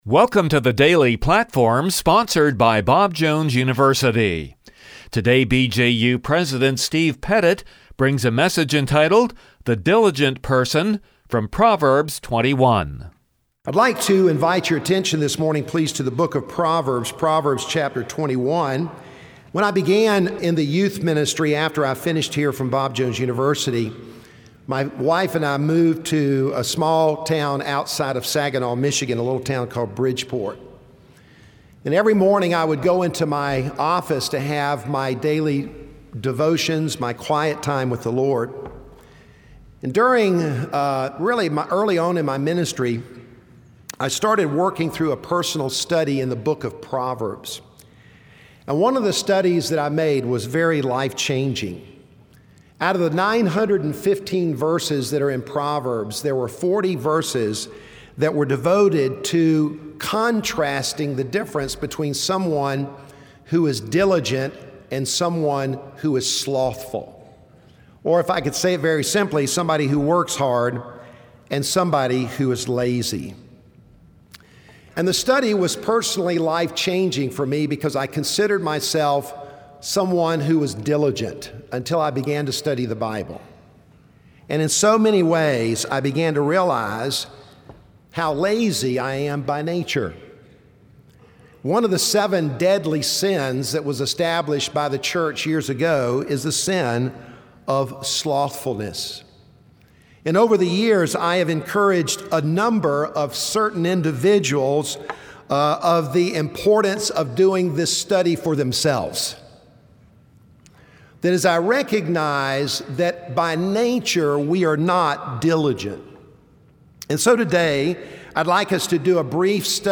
From the chapel service on 01/27/2016